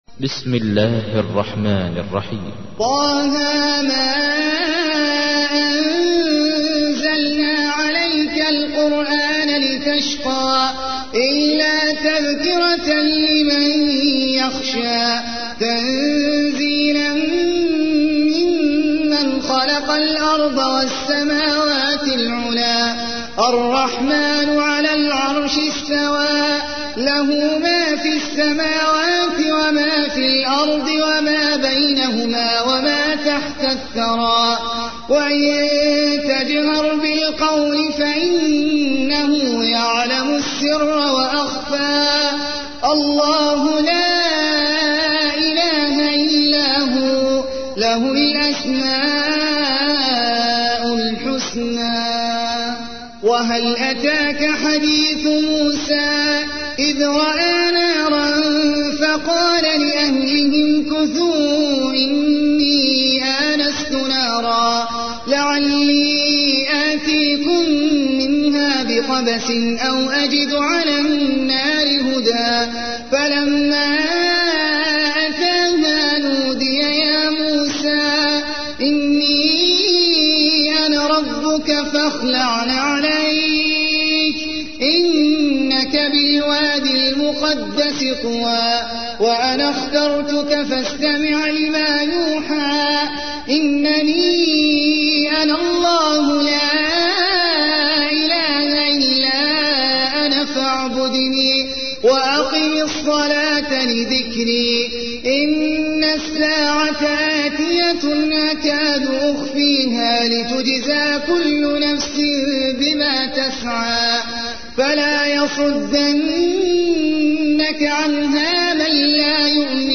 تحميل : 20. سورة طه / القارئ احمد العجمي / القرآن الكريم / موقع يا حسين